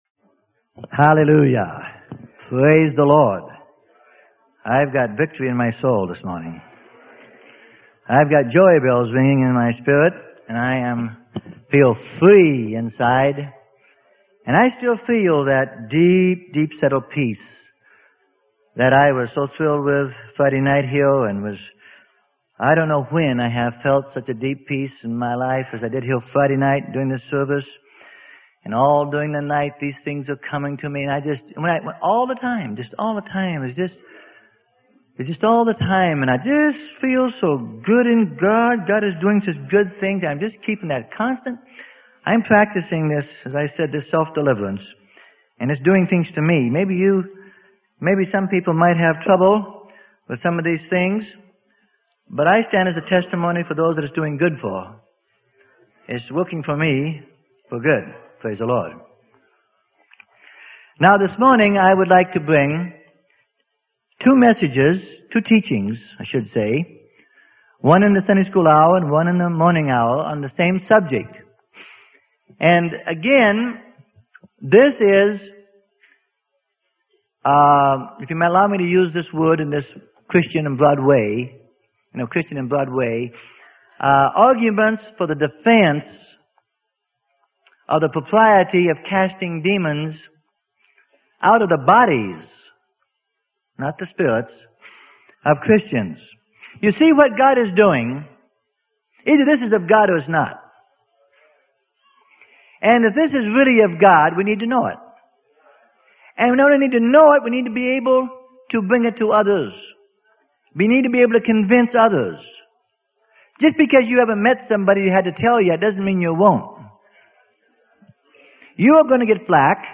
Sermon: Can Demons Lodge in the Bodies of Believers?